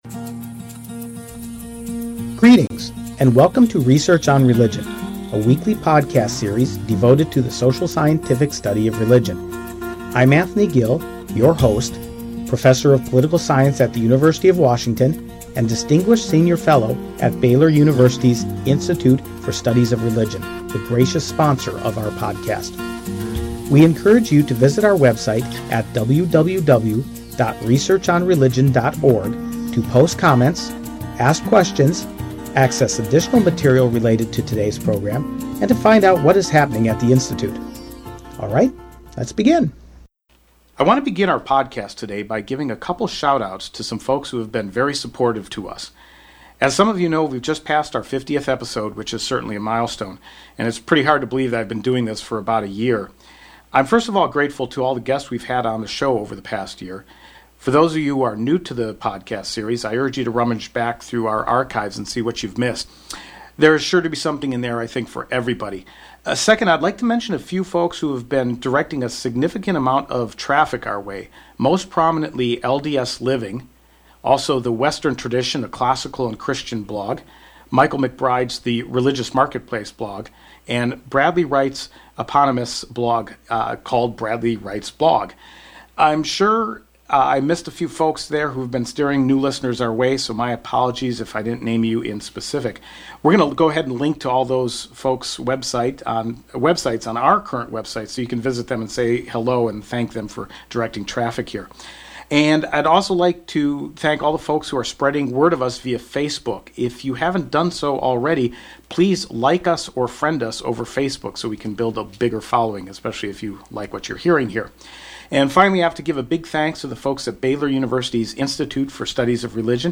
In the meantime, enjoy one of his favorite interviews from two years ago.